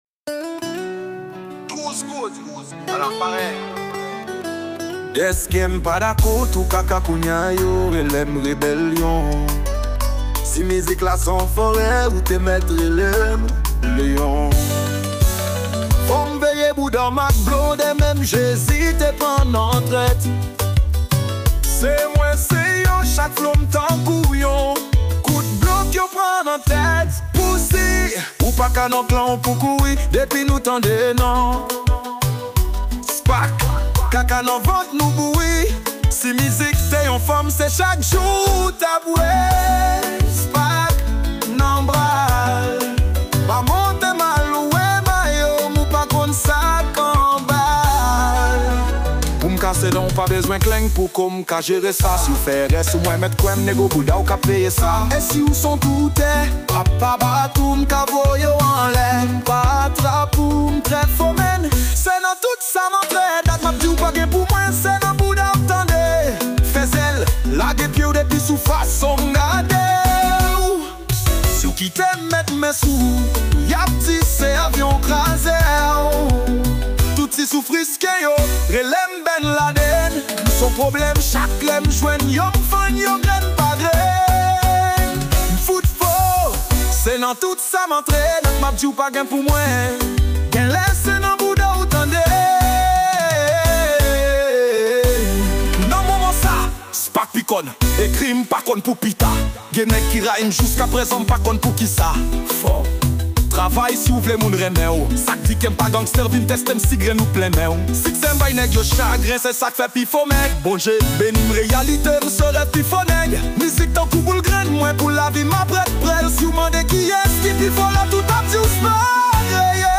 Genre : Konpa.